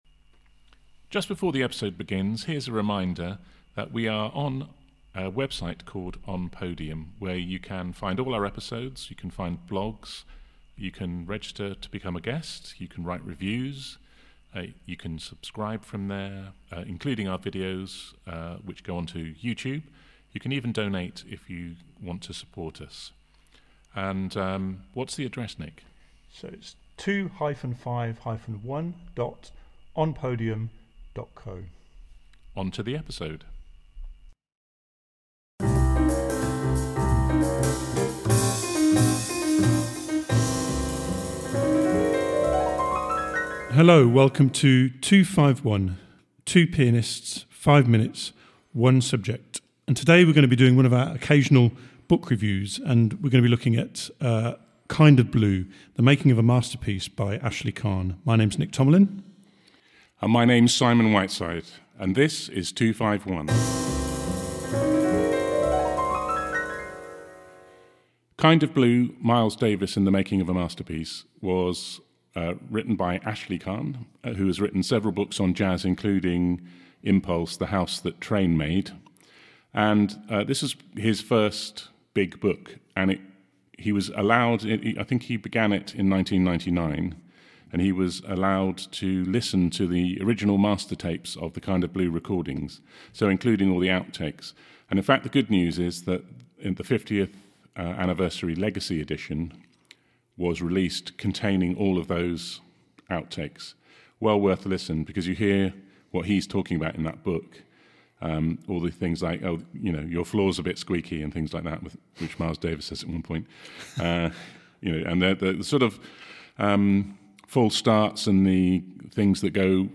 one of their jazz book reviews. Kind of Blue : The Making of the Miles Davis Masterpiece by Ashley Kahn (Granta 200)1 is an in-depth look at the legendary album, its creation and its legacy.